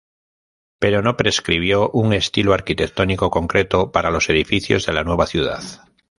con‧cre‧to
/konˈkɾeto/